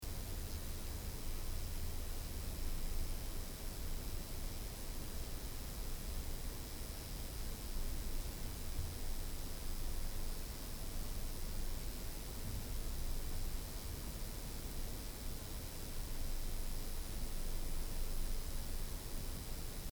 Da schwingt schon was mit 50 Hz mit, aber viel vordergründiger ist so eine Art Zischeln, was auch ein wenig moduliert und variiert. Ist auch unabhängig vom Mikrofon, also beim M 930 Ts klingt es genauso wie hier beim USM 96.